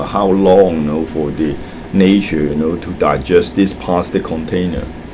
S1 = Taiwanese female S2 = Hong Kong male Context: S2 is talking about wastage and pollution. S2 : ... how long you know for the (.) nature you know to digest this plastic container Intended Words : plastic Heard as : past Discussion : There is no [l] in the word; in fact plastic is pronounced as [pɑ:stə] .